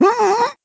06_luigi_hrmm.aiff